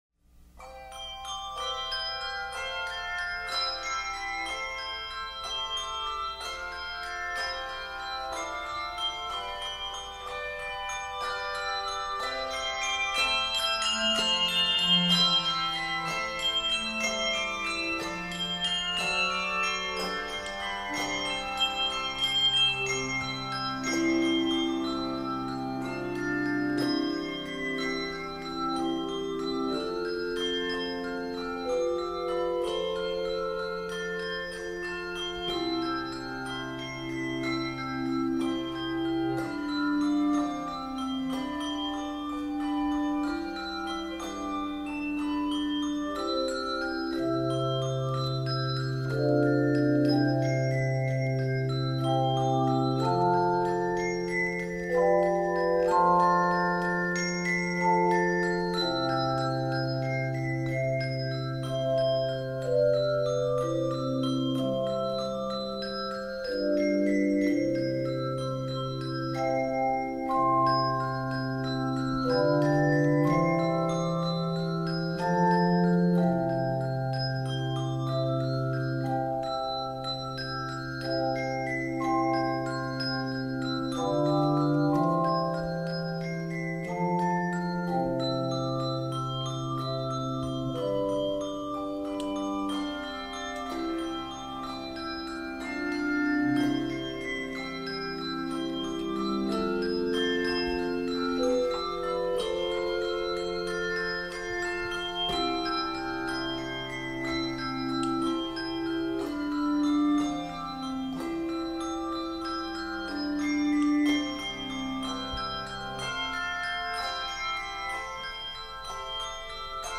Voicing: 3-5 Octaves
beautifully written setting of the familiar hymn
encourages expressive ringing